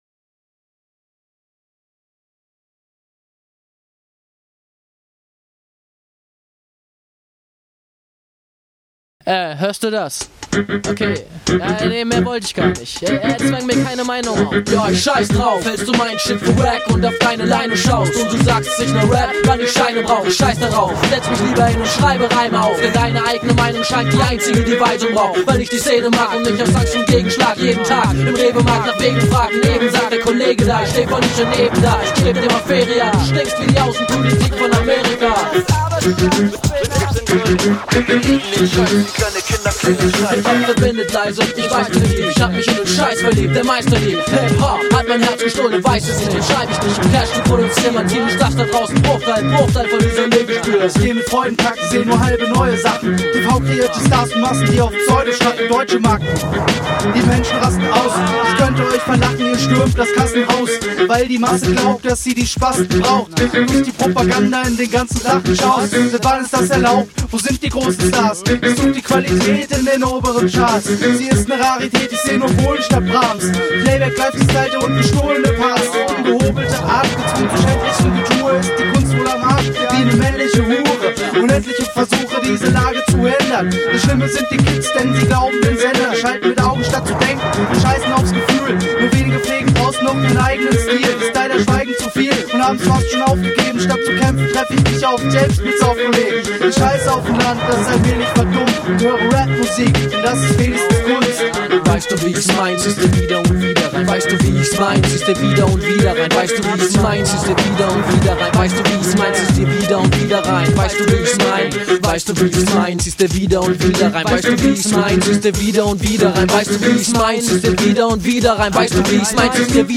HipHop project